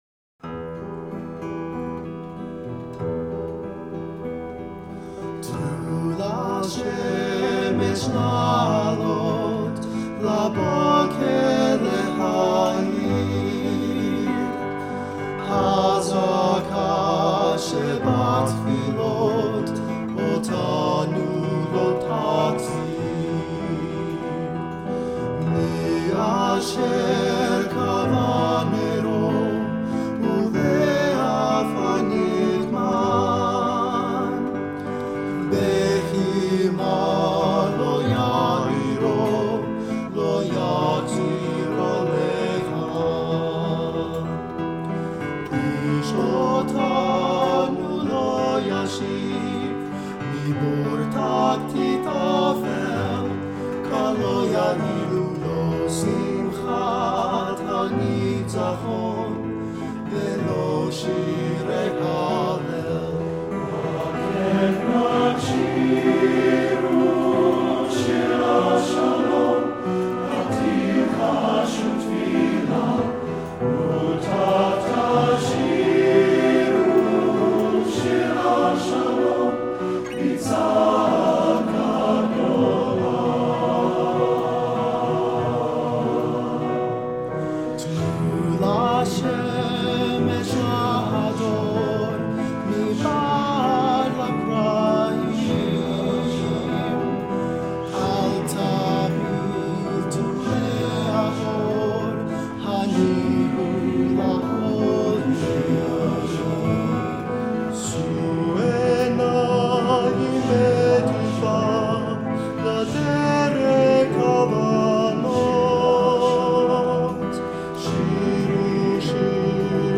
TTBB, piano or guitar